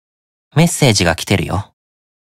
Belphegor_Chat_Notification_(NB)_Voice.ogg